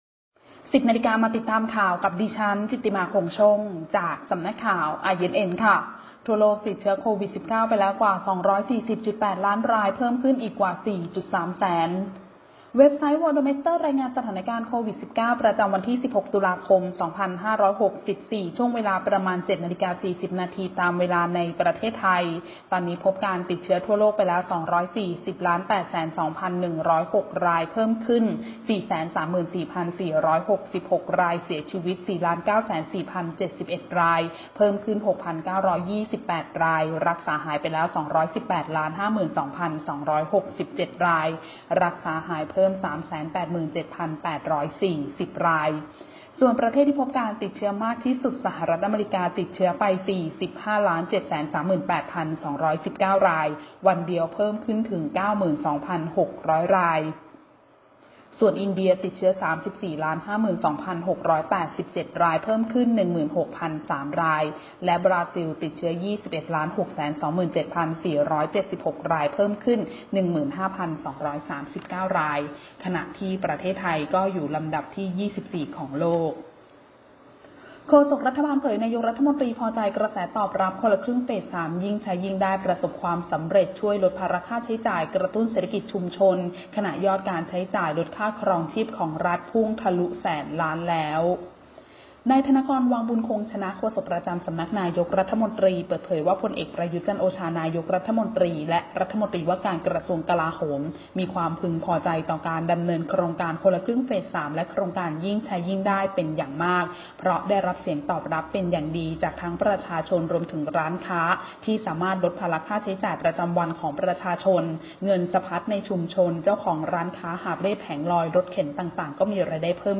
Video คลิปข่าวต้นชั่วโมง ข่าว